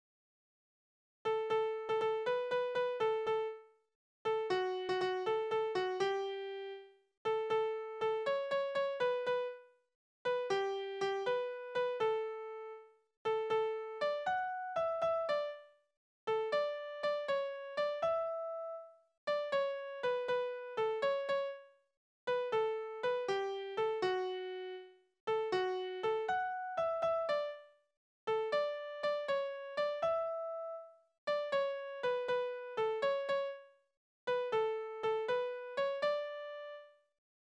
Balladen: Das Kind macht dem verlassenen Mädchen neuen Lebensmut
Tonart: D-Dur
Taktart: 6/8
Tonumfang: Oktave
Besetzung: vokal
Anmerkung: Vortragsbezeichnung: mäßig schnell Takte 18-22 laut Vorgabe ergänzt